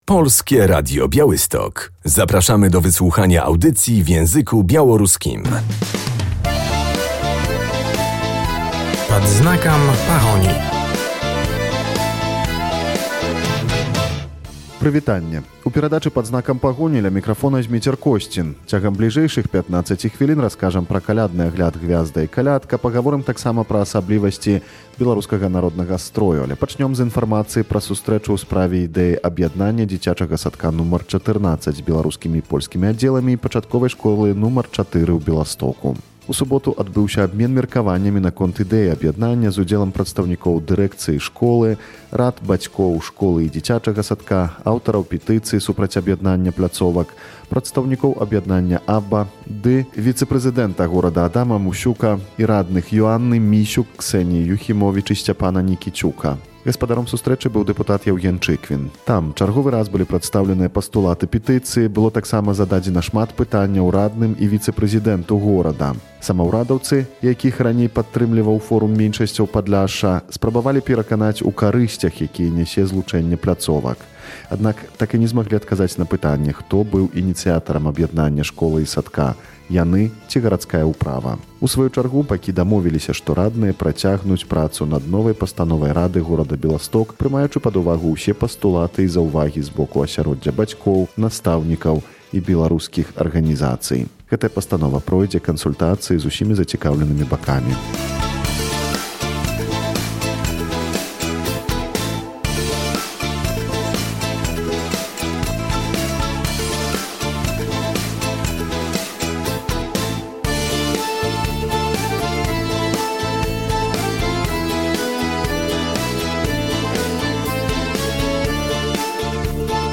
Można było usłyszeć współczesne, ale też tradycyjne, nieraz zapomniane kolędy. W Białymstoku odbyły się prezentacje zespołów kolędniczych "Gwiazda i kolęda", które od wielu lat organizuje Białoruskie Towarzystwo Społeczno-Kulturalne.